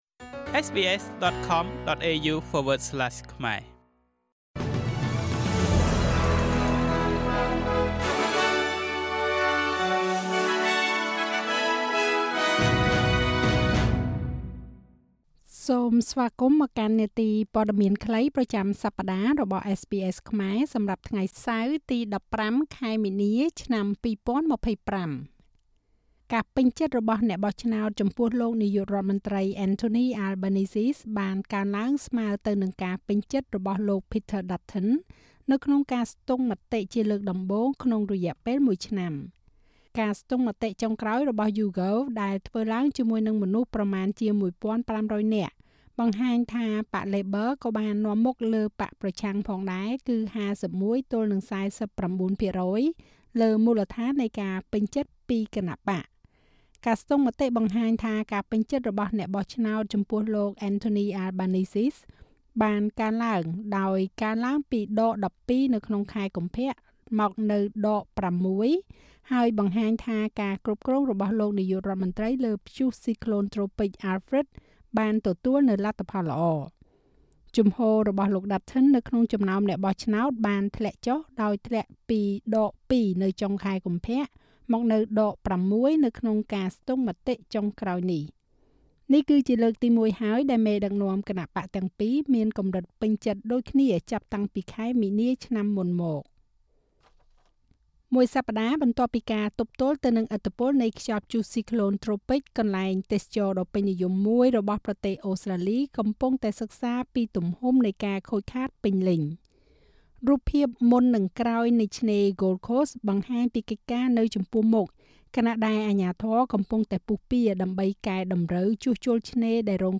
នាទីព័ត៌មានខ្លីប្រចាំសប្តាហ៍របស់SBSខ្មែរ សម្រាប់ថ្ងៃសៅរ៍ ទី១៥ ខែមីនា ឆ្នាំ២០២៥